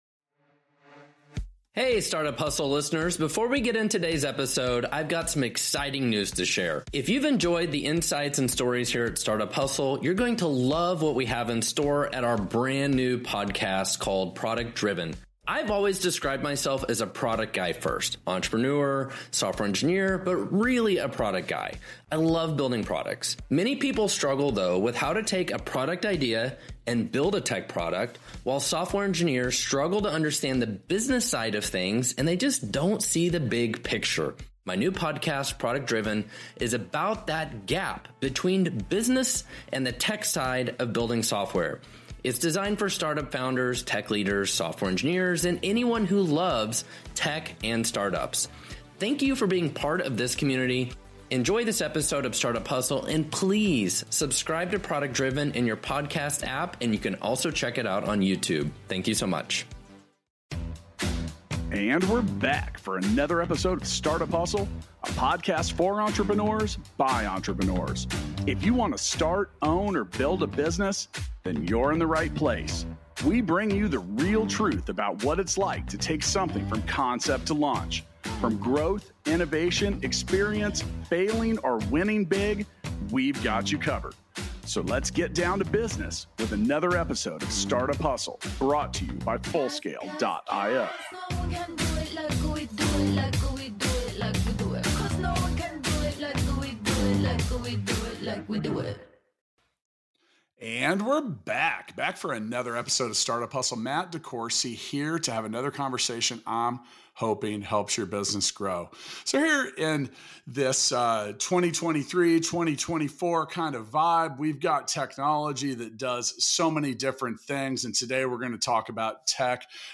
for an insightful conversation on leveraging technology for mental well-being. Dive into their discussion on addressing the opioid addiction crisis and the crucial need for accessible healthcare.